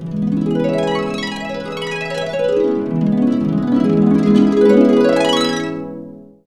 HARP F#X ARP.wav